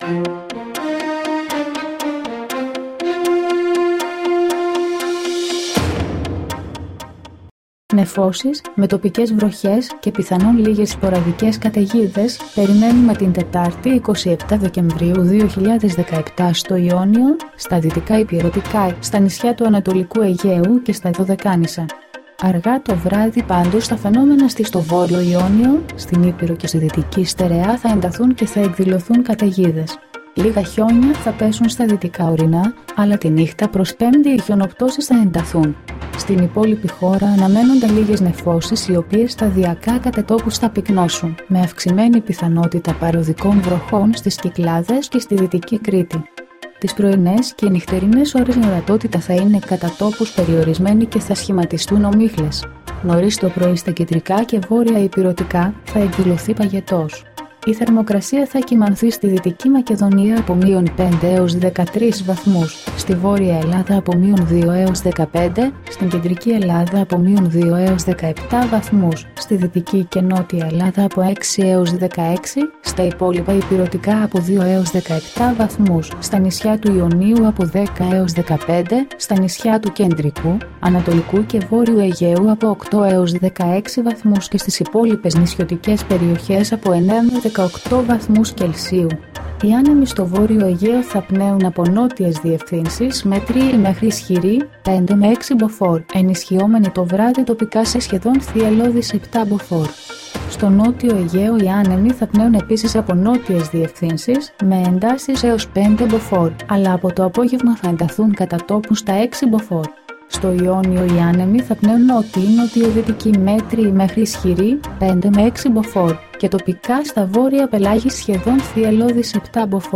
dailyforecast-53.mp3